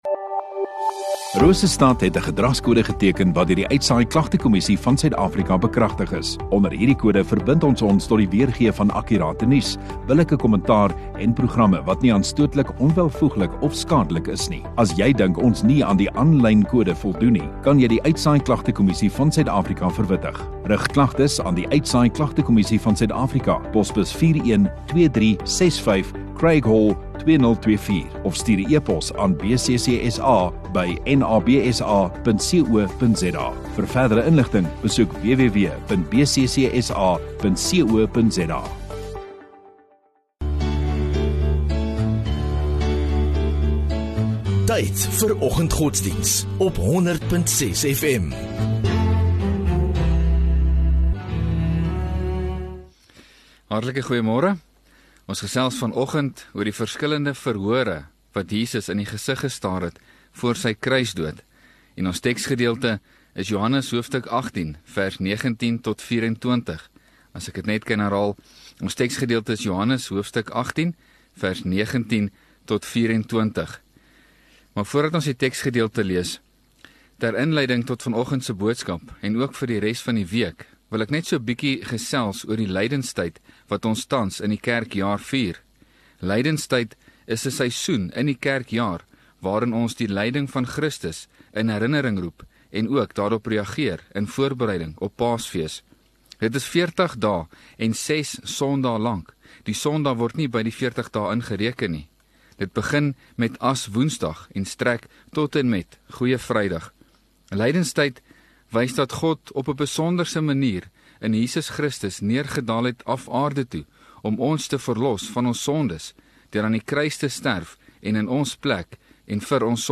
31 Mar Maandag Oggenddiens